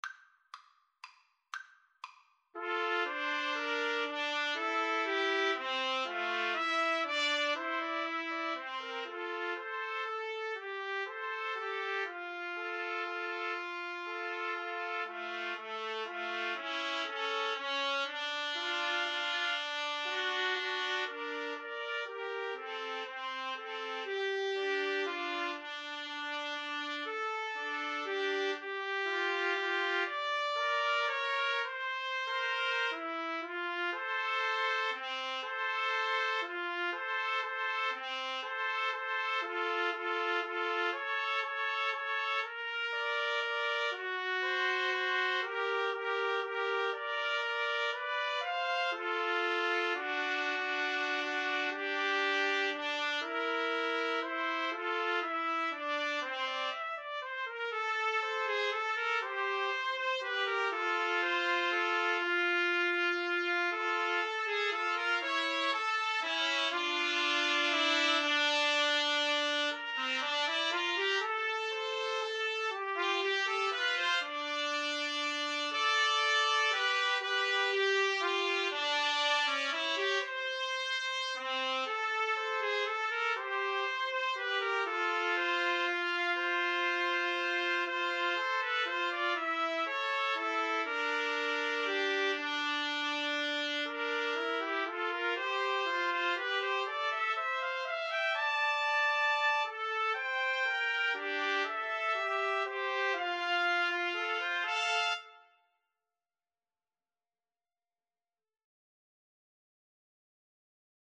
Trumpet 1Trumpet 2Trumpet 3
3/4 (View more 3/4 Music)
= 120 Tempo di Valse = c. 120